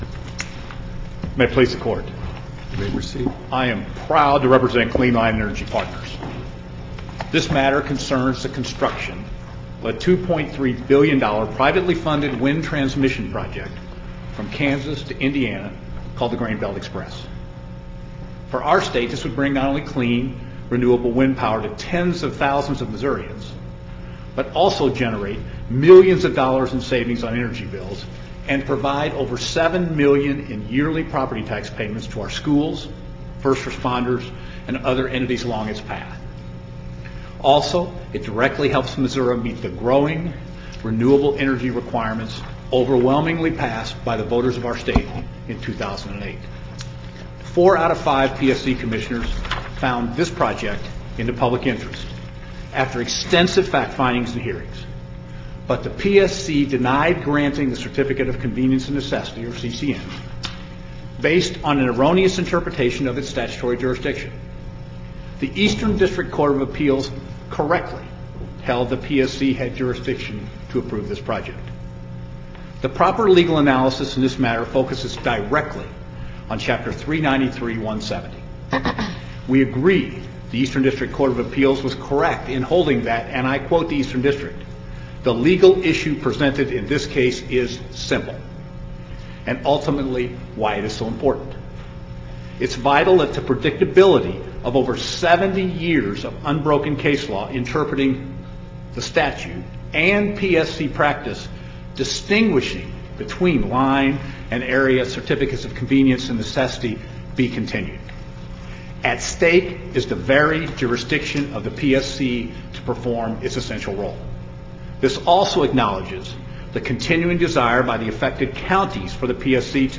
MP3 audio file of oral arguments in SC96731